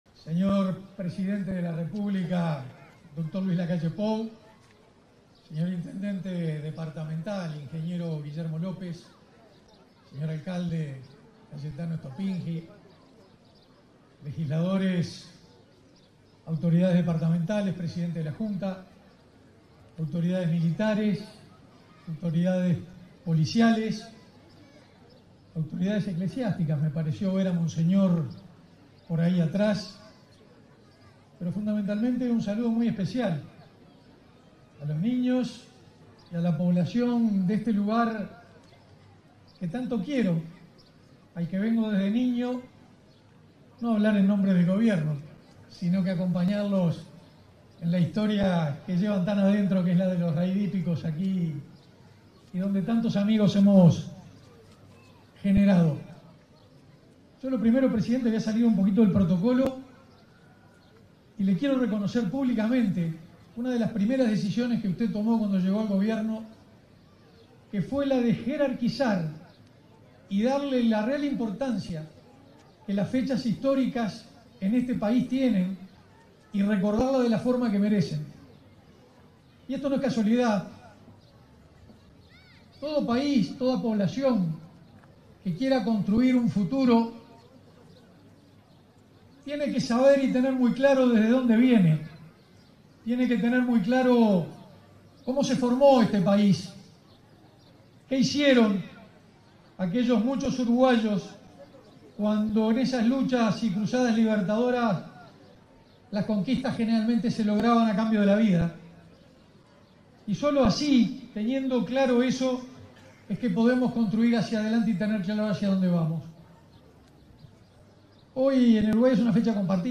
Palabras del ministro de Defensa Nacional, Armando Castaingdebat
En el acto conmemorativo central del 199.° aniversario de la Batalla de Sarandí, este 12 de octubre, se expresó el ministro de Defensa Nacional,